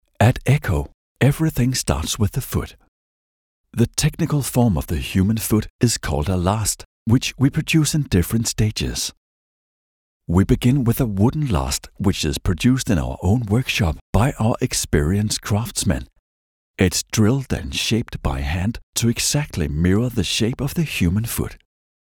Male
Authoritative, Confident, Cool, Corporate, Engaging, Friendly, Natural, Reassuring, Sarcastic, Smooth, Soft, Warm, Versatile
Microphone: Neumann TLM 102
Audio equipment: pro sound booth